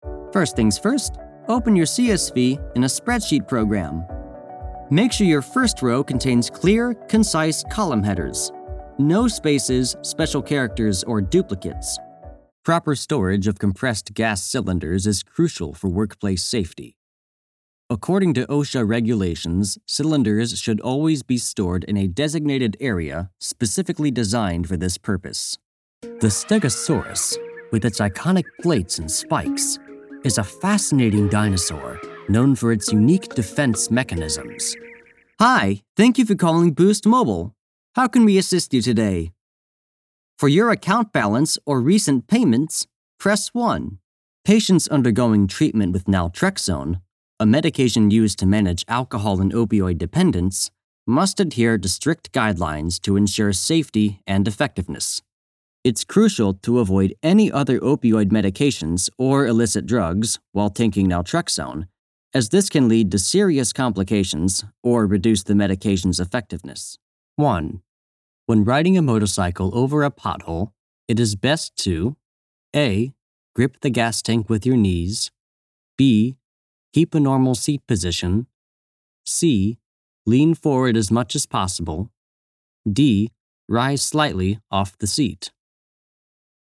Demo Reels